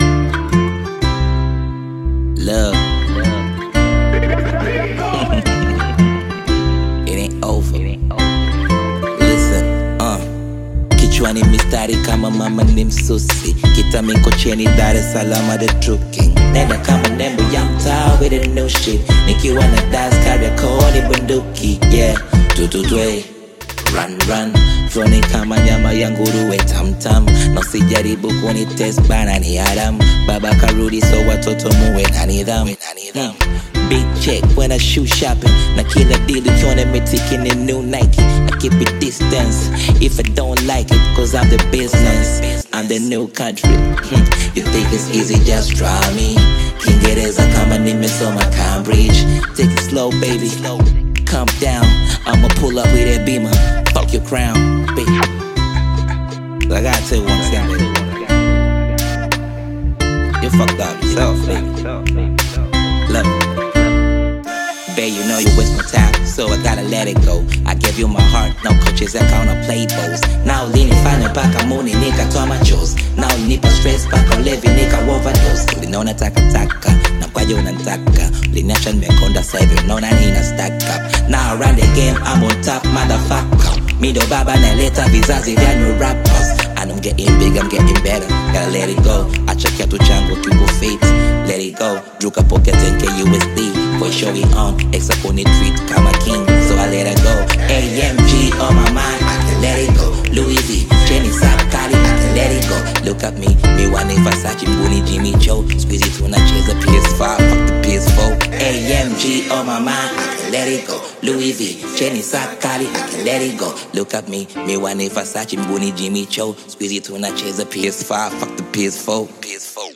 Bongo Flava
Through his heartfelt vocals and poetic songwriting